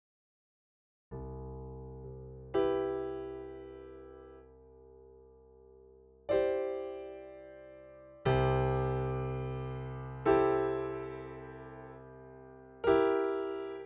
Δύο Grand Piano. Το ένα είναι γραμμενο στο cakewalk με σύγχρονο vst (Analog Lab V- Arturia ) και το αλλο στο cubase sx 2.2 με το Hypersonic της steinberg (20 + χρόνια πριν).